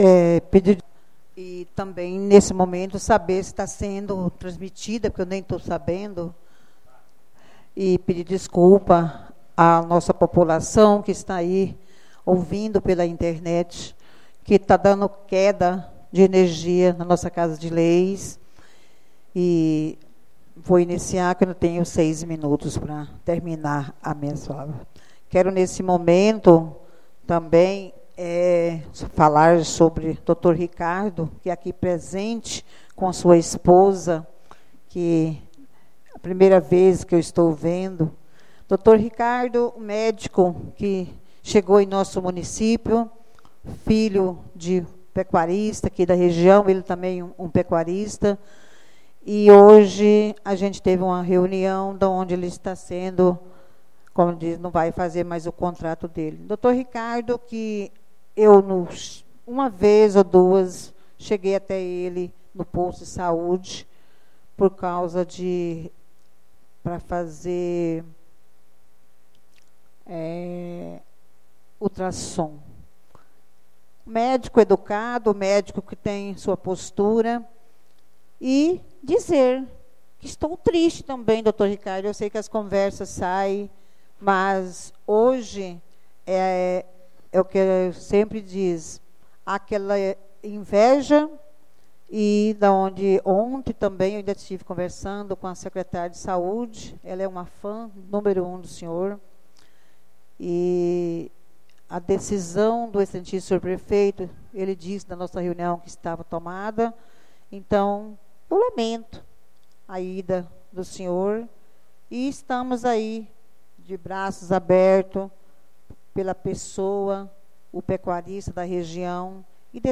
Áudio Sessão Ordinária 6º Parte